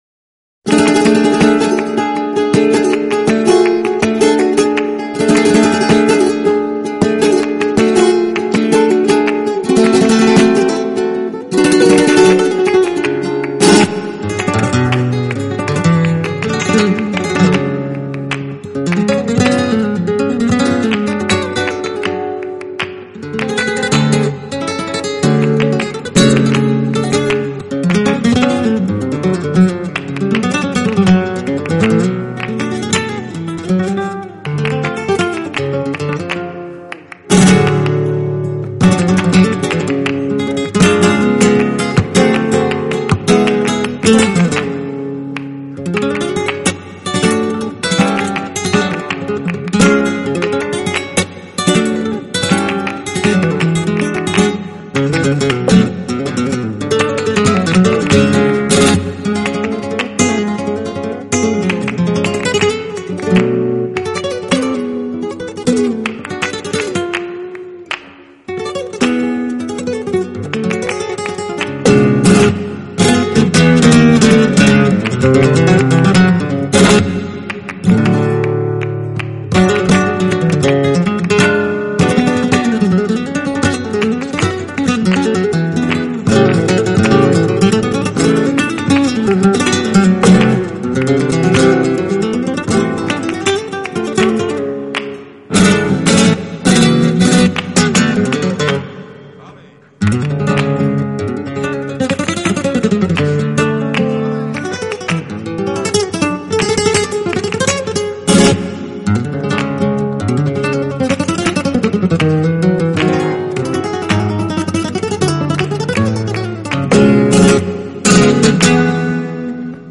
作品风格融合了西班牙传统民谣吉他演奏手法与现在爵士音乐的吉他技艺，将两者充分 发挥和谐融合，整体质感丰富。